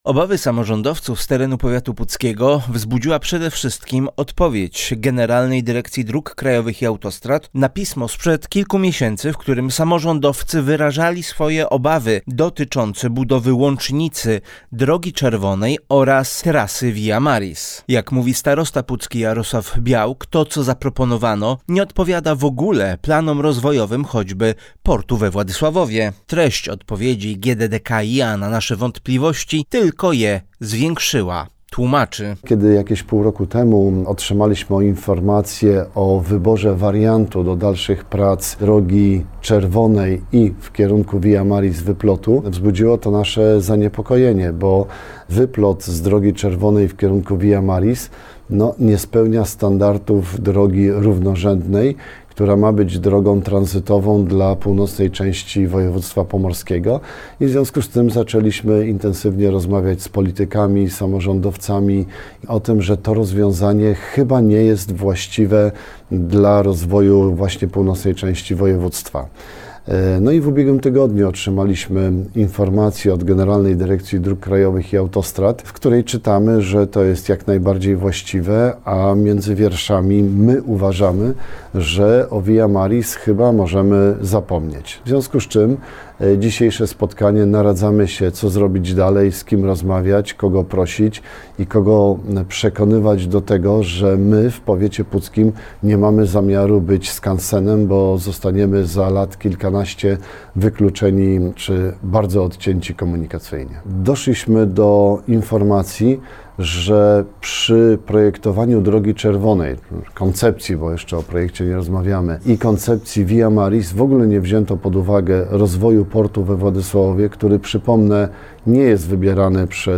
Treść pisma tylko zwiększyła nasz niepokój w sprawie całej inwestycji – mówi starosta pucki Jarosław Białk.